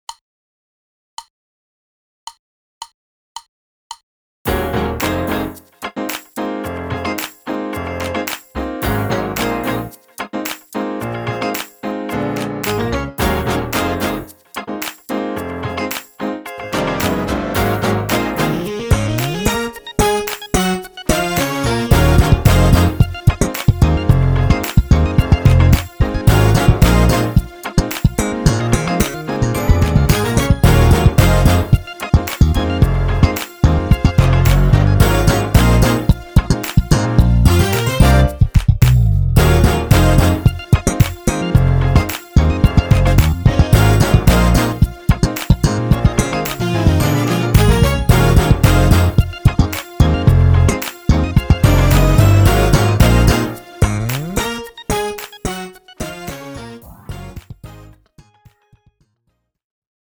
Gospel based play along song
Each song will start you off with a 4 or 8 count click.
Tempo: 110bpm Key: Dbmaj